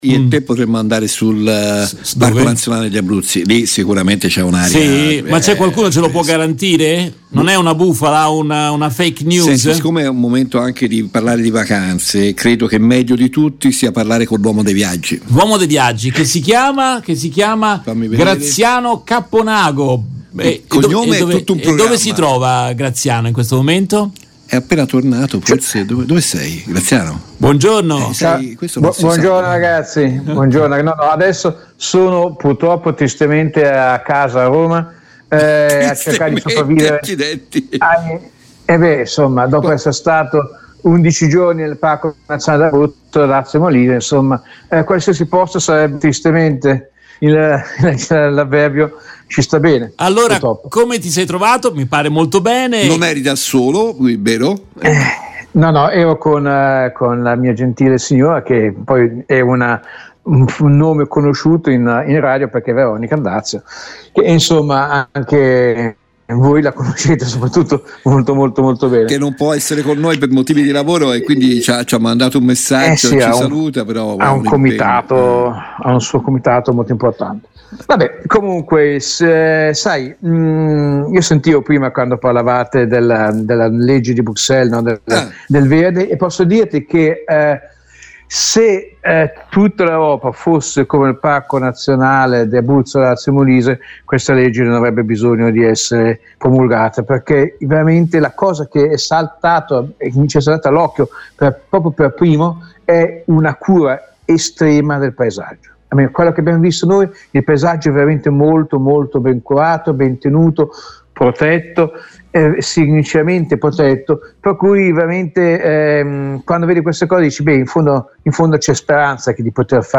Nel corso della diretta del mattino su RVS